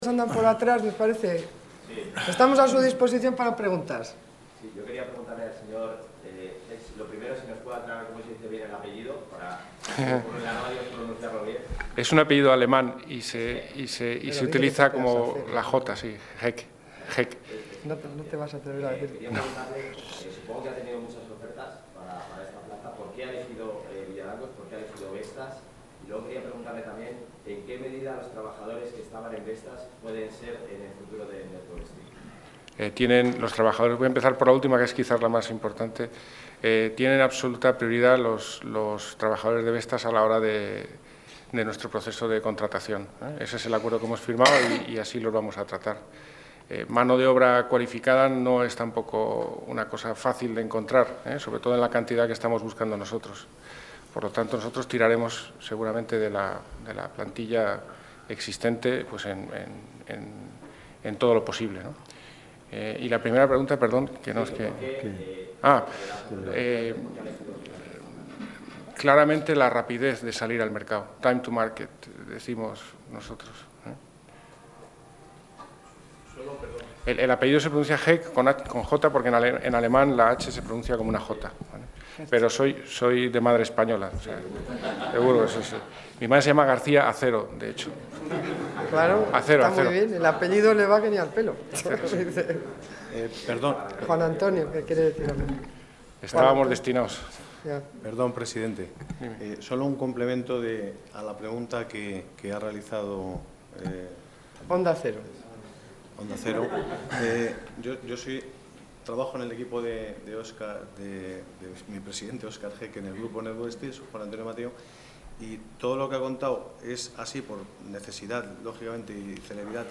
Declaraciones de la consejera de Economía y Hacienda.